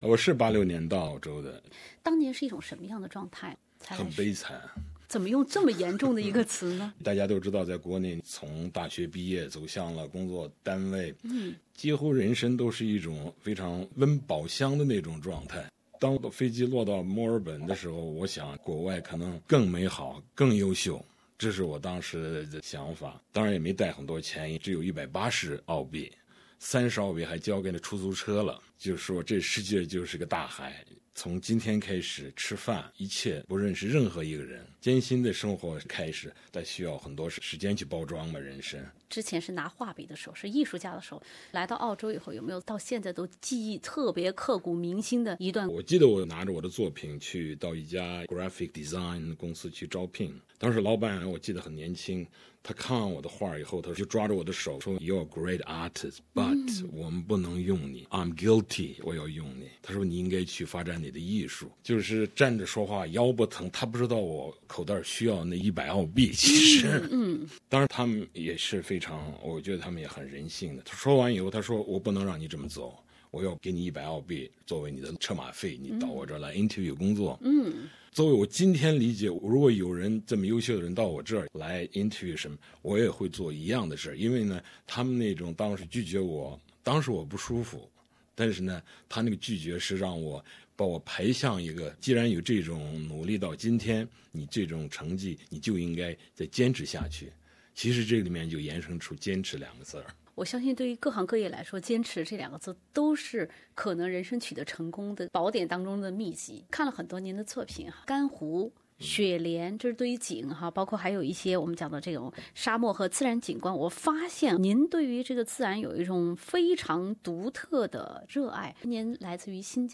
面对面的倾听这位身材魁梧、气度不凡的维族画家，讲述他的故事。